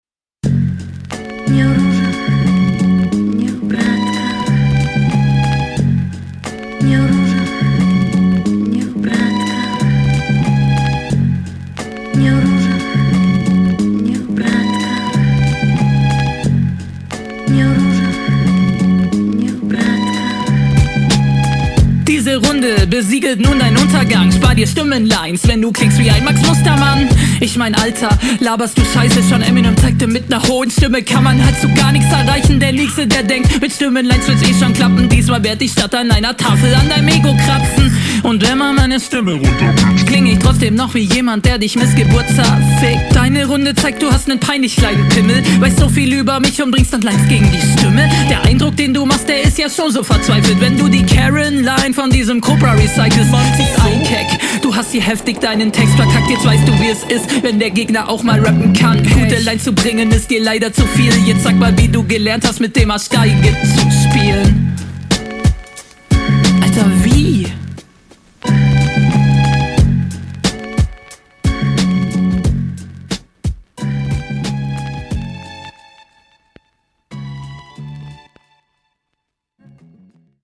besser gemischt als dein kontrahent